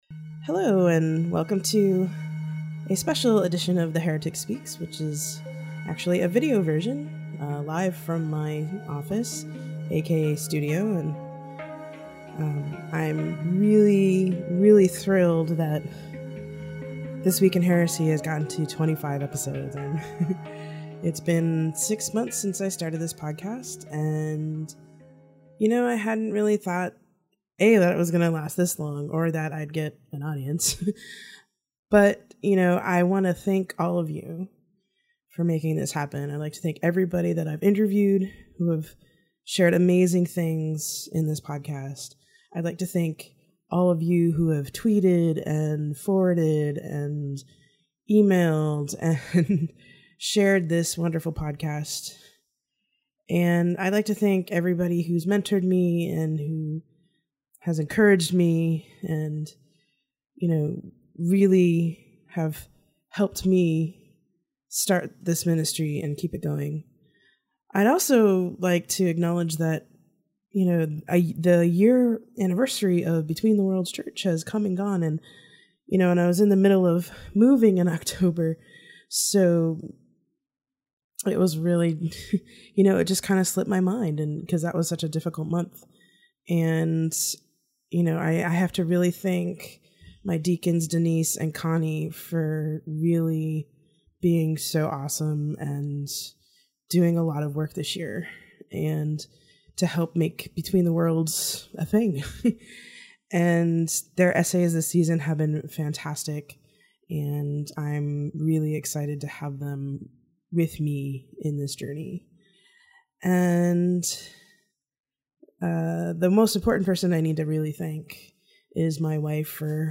Recording, oral